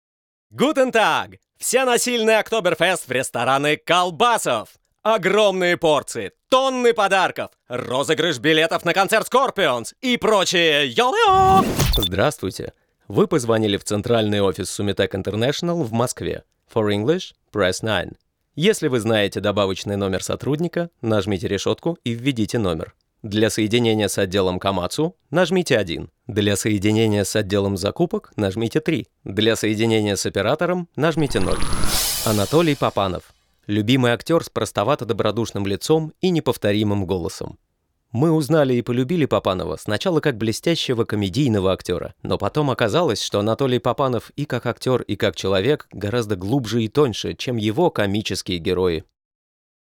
Баритональный тенор с большим певческим опытом в разных стилях (рок, мюзикл), что добавляет красок и возможностей для озвучивания в широком спектре жанров и стилей.
Тракт: SE2200t -> NI Komplete audio 6